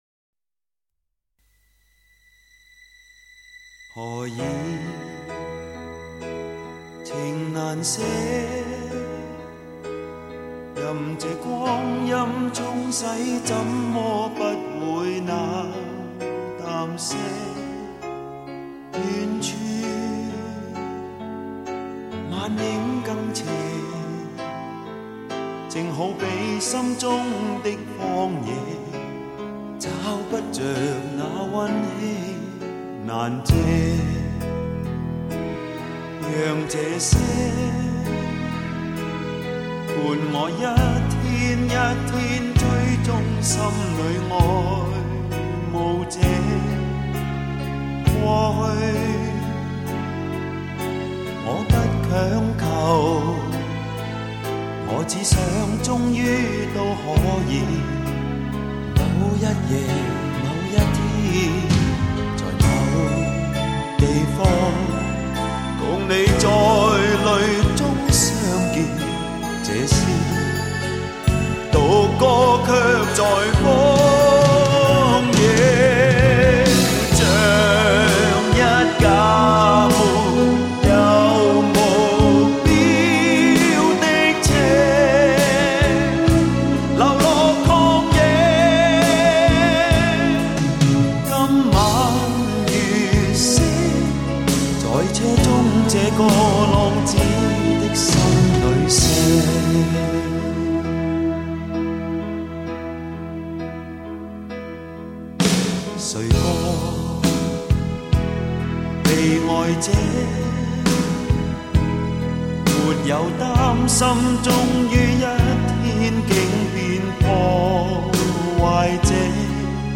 香港DSD版
专辑歌曲的电子元素添加了华丽感，整体氛围也很积极，因为是夏天之前推出，尽管透露出紧张和困惑，气质上还是不乏希望。